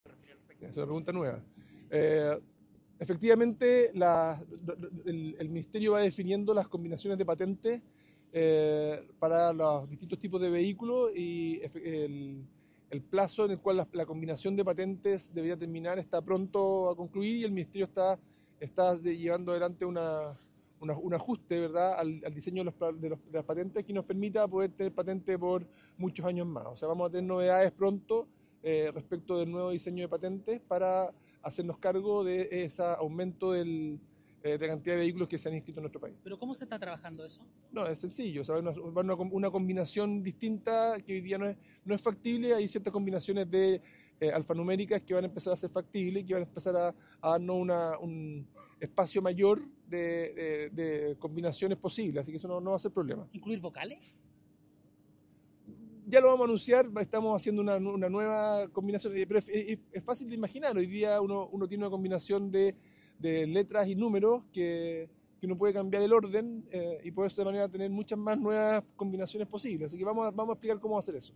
En conversación con Radio ADN, Juan Carlos Muñoz dio luces de lo que serían las nuevas combinaciones.